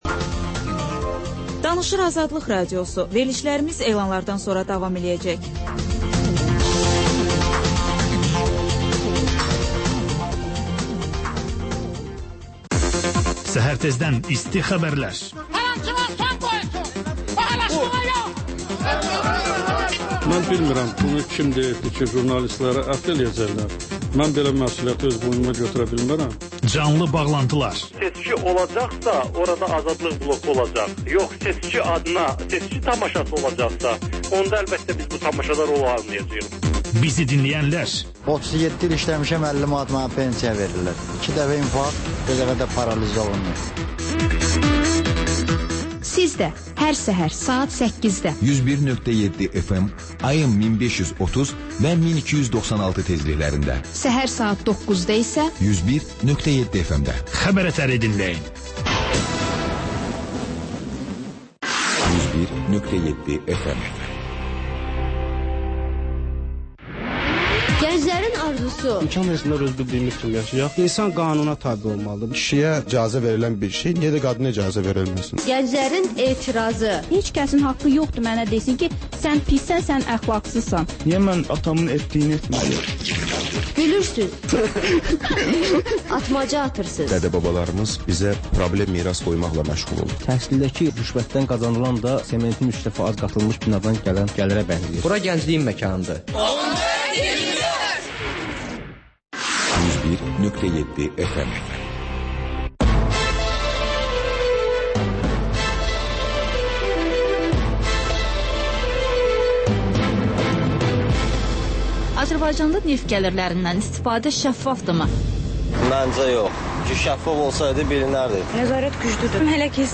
Xəbərlər, sonra CAN BAKI: Bakının ictimai və mədəni yaşamı, düşüncə və əyləncə həyatı… Həftə boyu efirə getmiş CAN BAKI radioşoularında ən maraqlı məqamlardan hazırlanmış xüsusi buraxılış